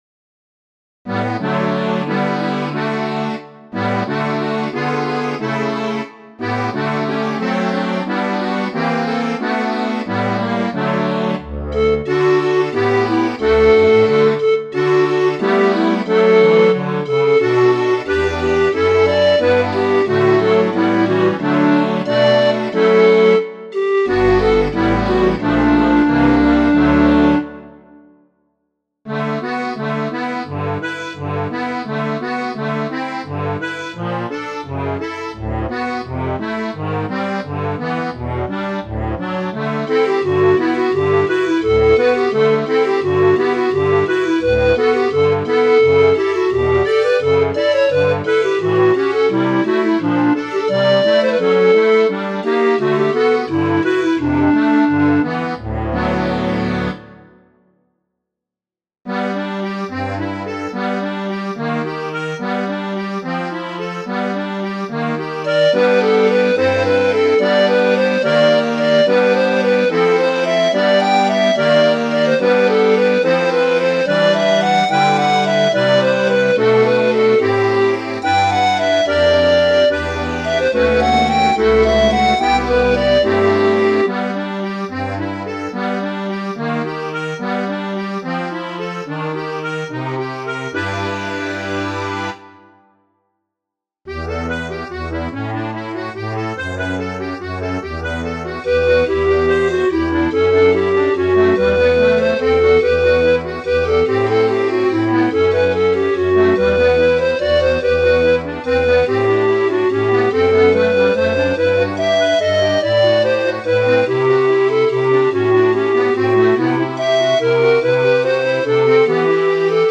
Que notre Alsace est belle ! – Variations sur une chanson populaire alsacienne – Pour flûte de Pan et harmonium, ou 1 instrument mélodique et clavier.